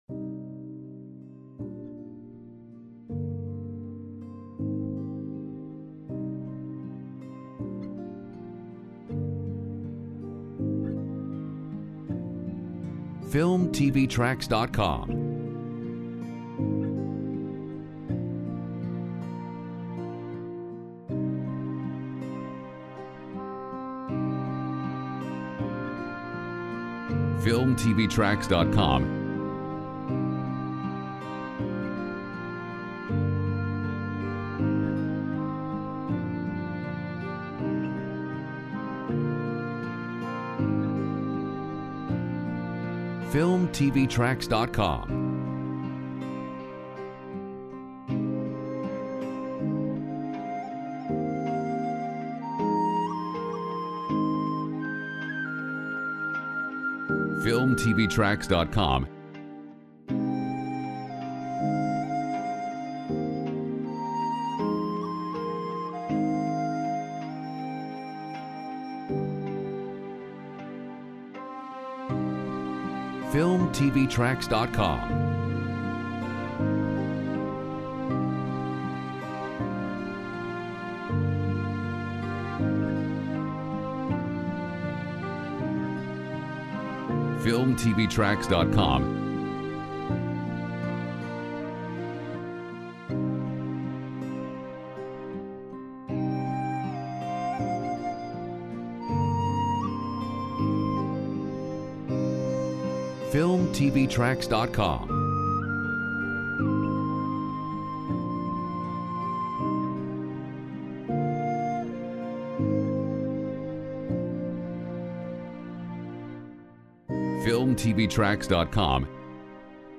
Genres: Piano, Orchestral
Mood: Calm, inspiring, uplifting, emotive